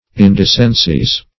indecencies - definition of indecencies - synonyms, pronunciation, spelling from Free Dictionary
Indecency \In*de"cen*cy\, n.; pl. Indecencies. [L. indecentia